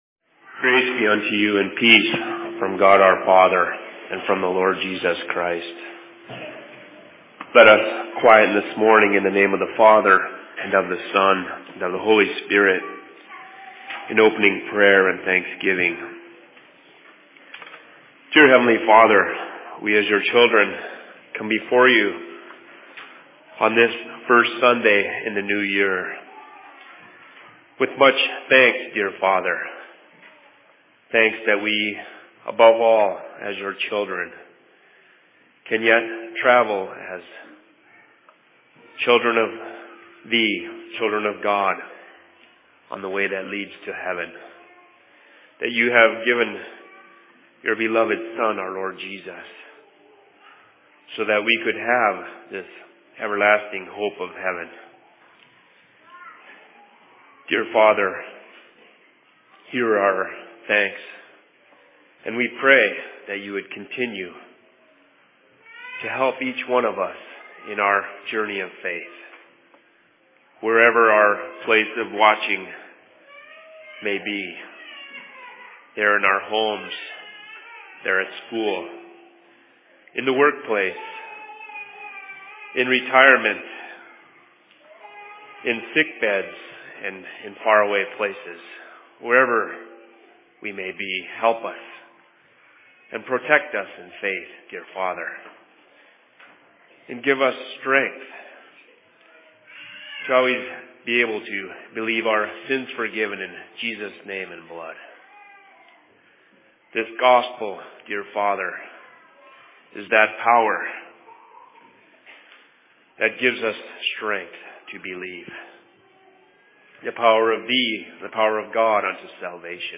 Sermon in Seattle 05.01.2014
Location: LLC Seattle